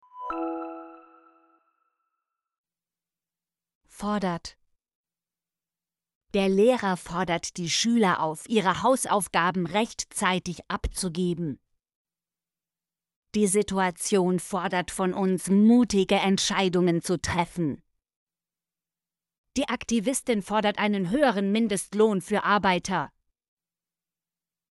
fordert - Example Sentences & Pronunciation, German Frequency List